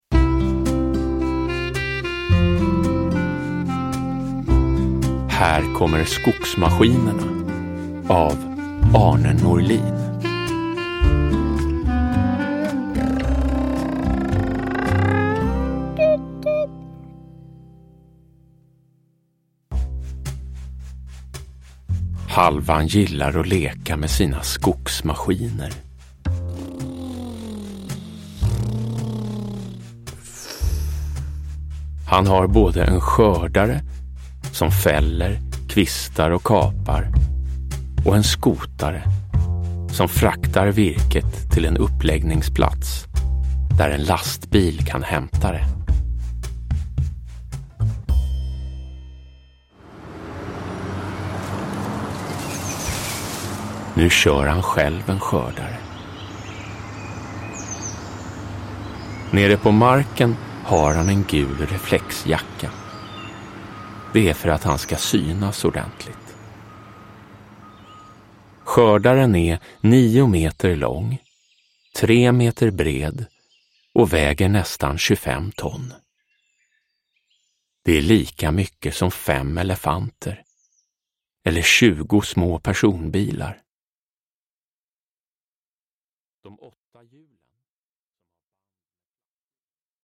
Här kommer skogsmaskinerna – Ljudbok – Laddas ner